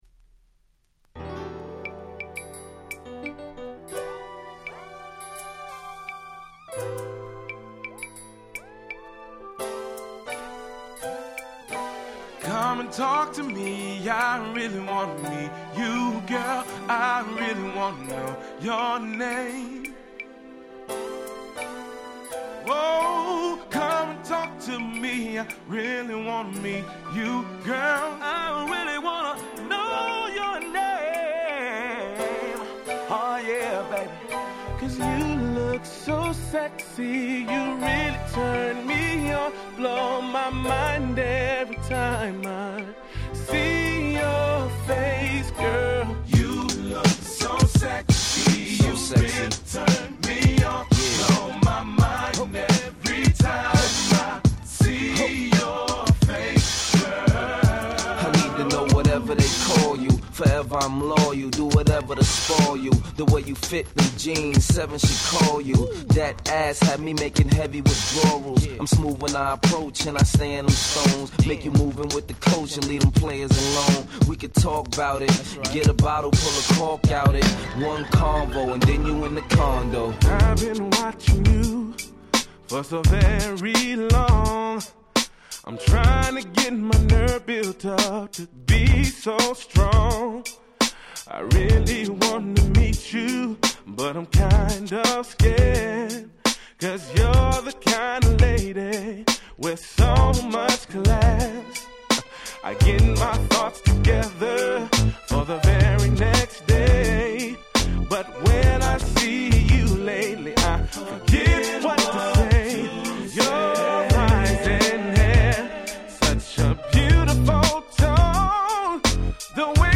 【Media】Vinyl 12'' Single (Promo?)
オリジナルに忠実なぶっといインピーチビートが気持ちの良い良リメイク！
ヒップホップソウル